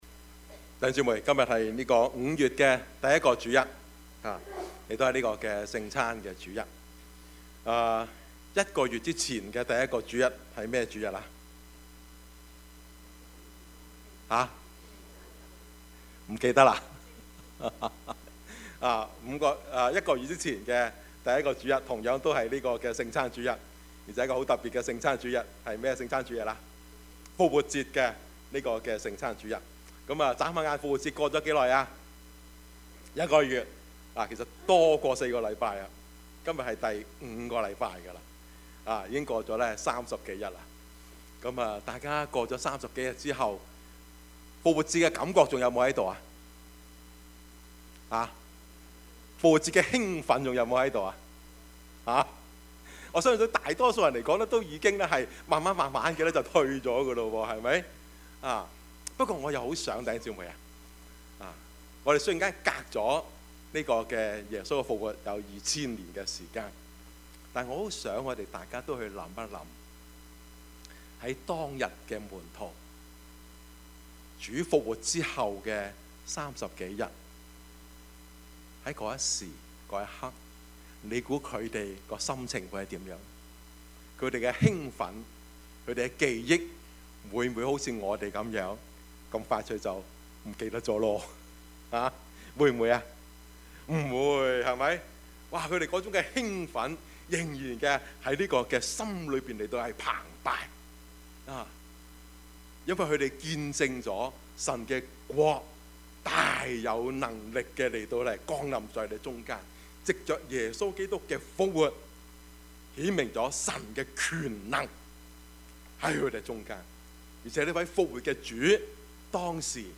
Service Type: 主日崇拜
Topics: 主日證道 « 多結果子 什麼榜樣？